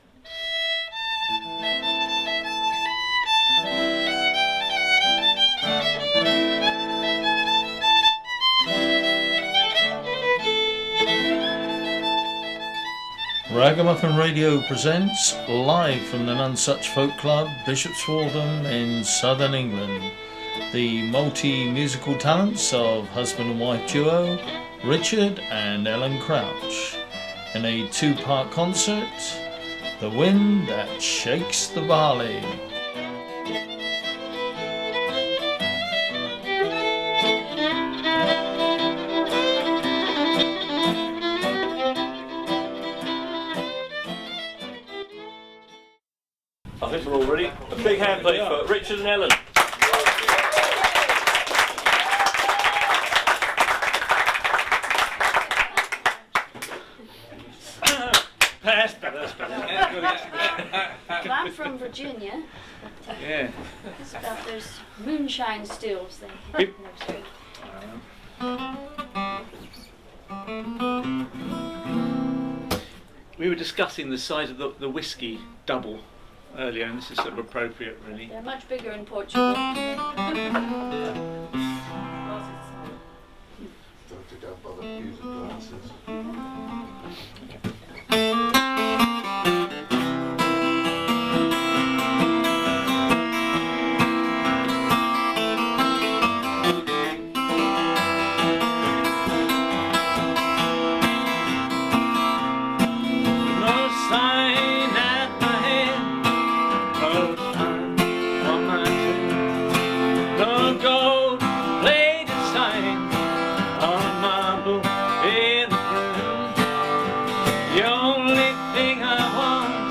Listen to the latest Sunday in the Shires show Time to put some wood in the stove, the coffee pot on the boil, butter the toast and play some music for a Sunday morning.